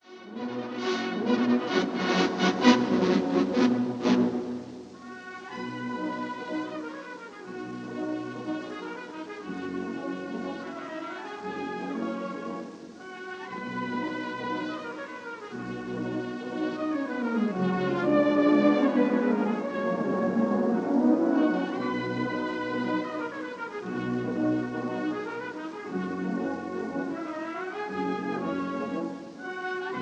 Recorded live in the Crystal Palace, London